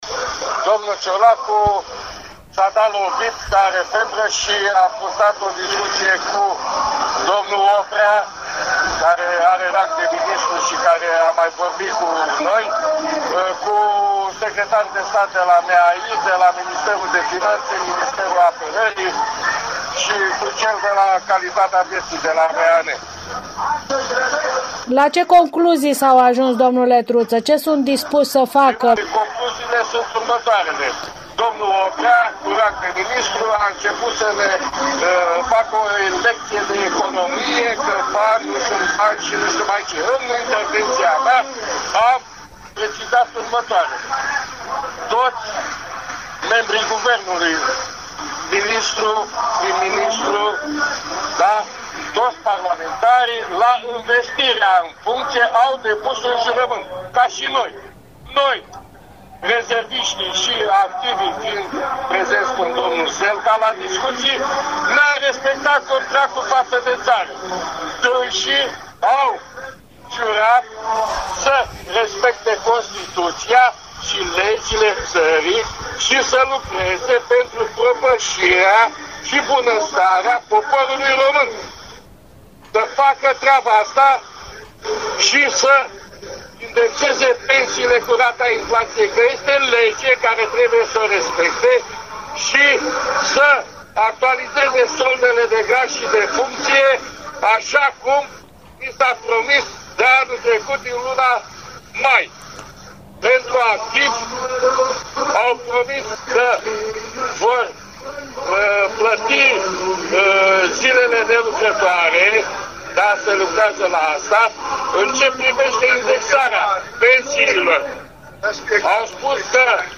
Acțiuni de protest au fost organizate, astăzi, de către Sindicatul Cadrelor Militare Disponibilizate şi Asociaţia Militarilor din România (ROMIL), în Piaţa Victoriei din capitală.